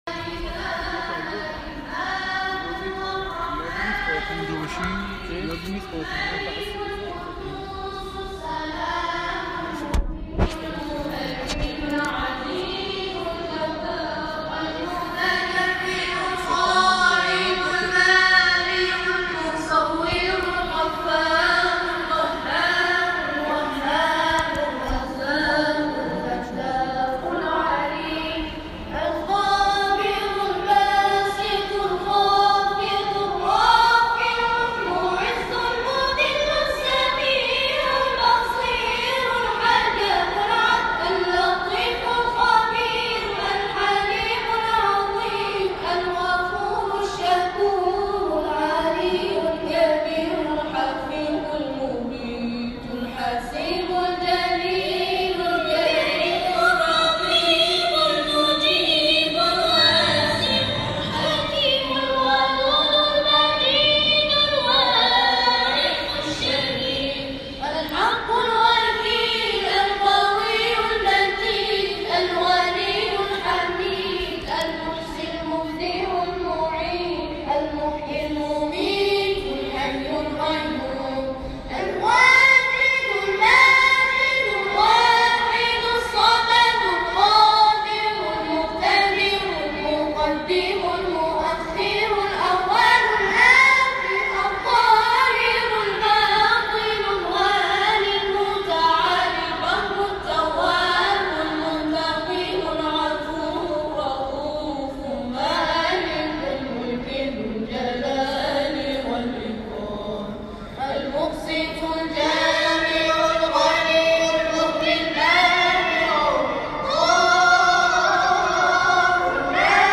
اجرای گروه تواشیح نخبگان خمینی شهر در محفل انس با قرآن؛ حسینیه ثارالله (ع) خمینی شهر